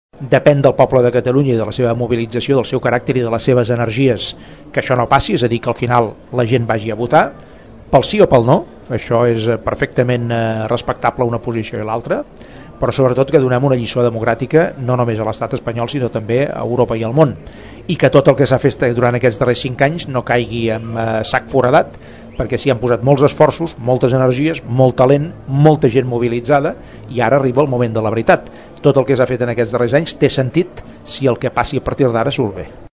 A Tordera, Artur Mas va voler destacar la capacitat de mobilització de la gent durant aquests darrers anys, entenent que són la força que empeny el procés independentista endavant.